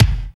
12 CMP KICK.wav